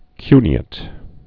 (kynē-ĭt, -āt)